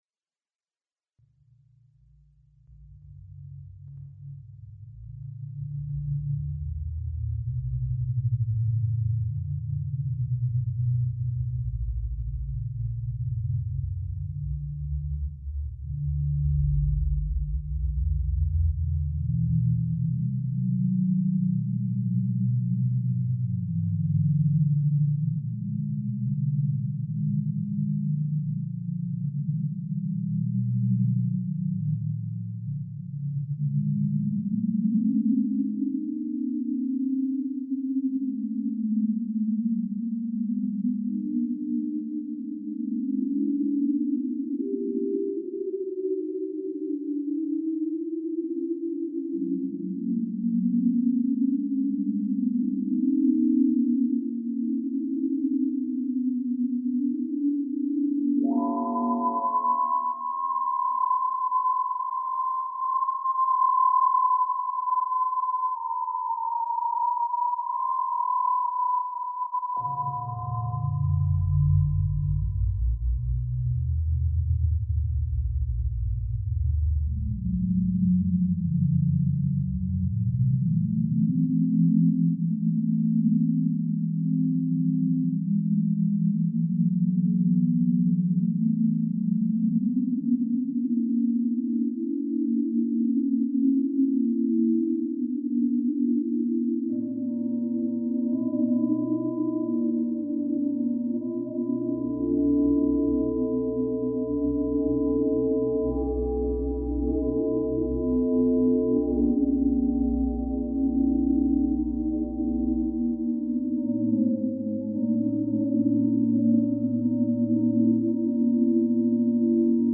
BUTTERFLY - system Nuova improvvisazione dimostrativa Live recording In questa registrazione, effettuata con l'esclusivo utilizzo del sistema, in tempo reale e senza audio-editing , si dimostra la potenzialit� strumentali. L'algoritmo utilizzato contiene un modulo FM (parallelo e seriale), un banco di oscillatori (oscbank~) ed uno spazializzatore audio (Pluggo audiorate-pan) .